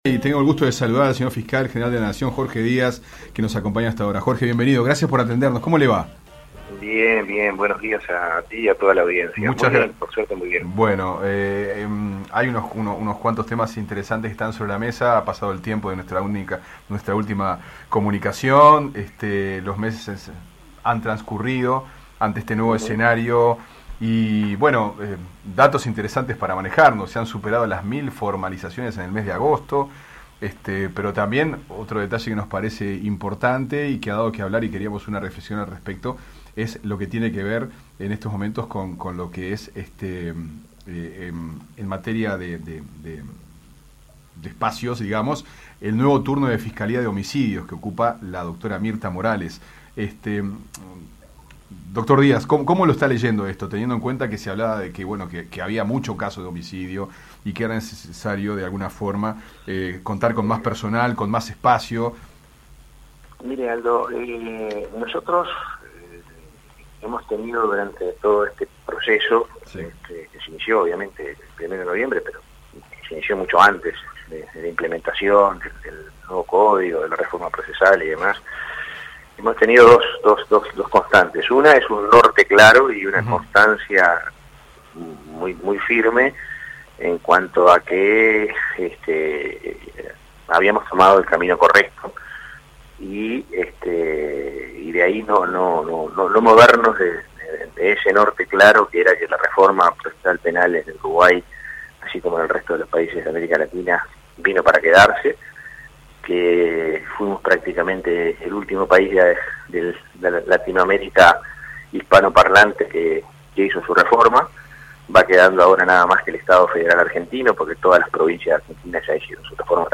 El Fiscal General de la Nación, Jorge Diaz detalló en Fuentes Confiables la creación de nuevas Fiscalías que busca optimizar la tarea.
Entrevistado